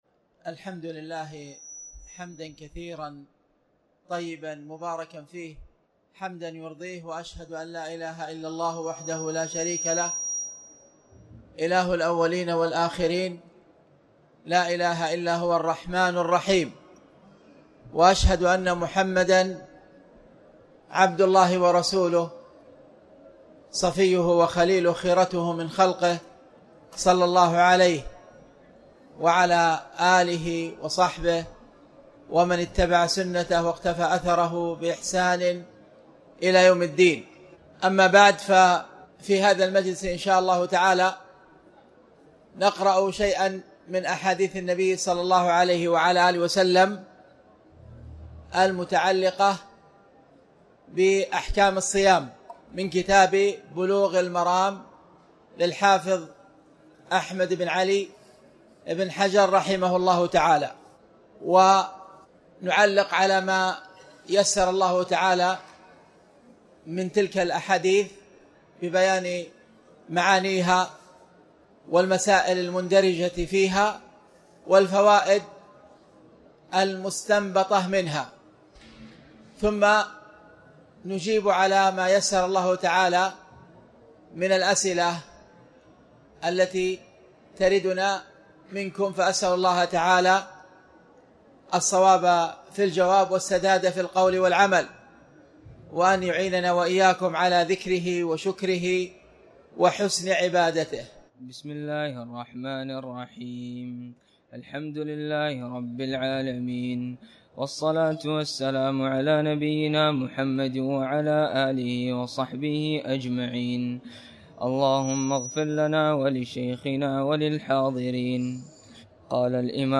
تاريخ النشر ٣ رمضان ١٤٣٩ هـ المكان: المسجد الحرام الشيخ